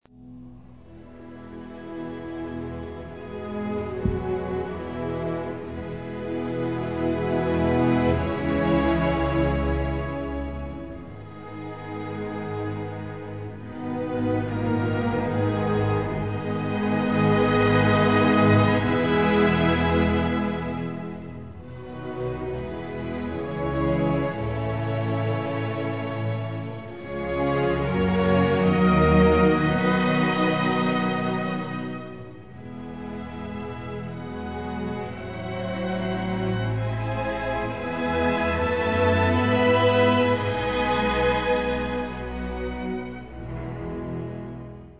Malgré  (ou grâce à ?) le manque de technique, son orchestration présente quelques effets particulièrement réussis et originaux.